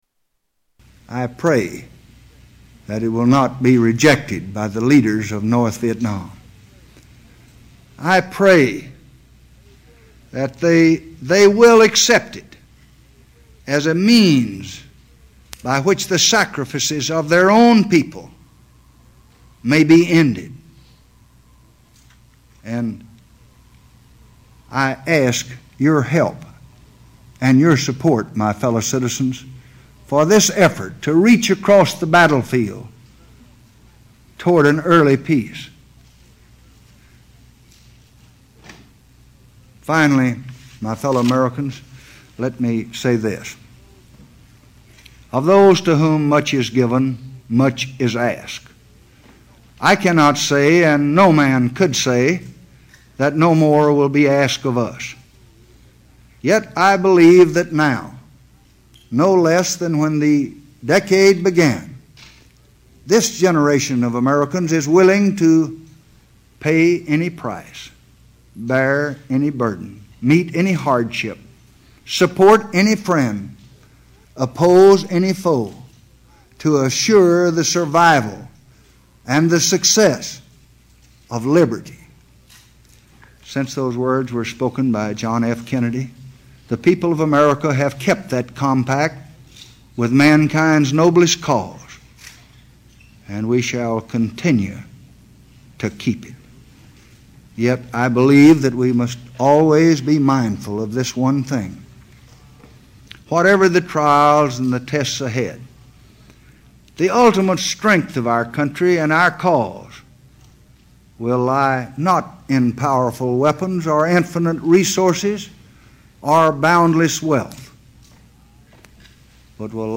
Renunciation Speech Part 6
Tags: Historical Lyndon Baines Johnson Lyndon Baines Johnson clips LBJ Renunciation speech